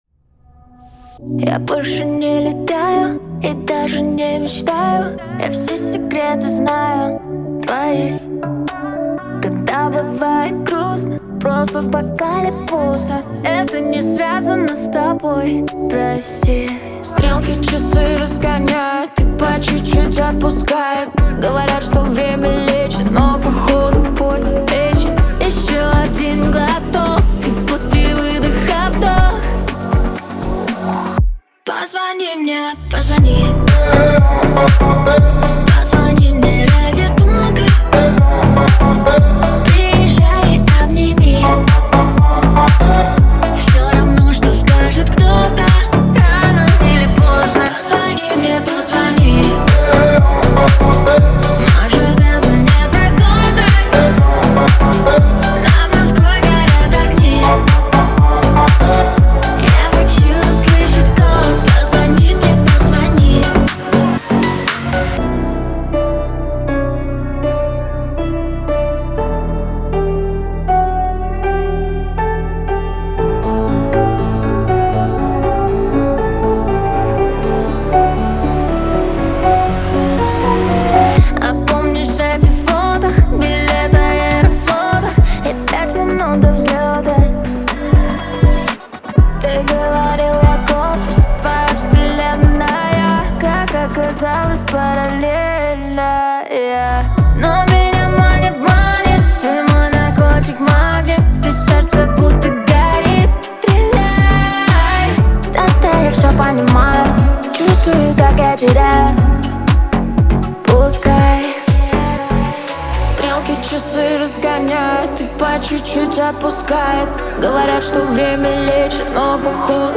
весёлая хорошая музыка